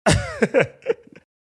laughs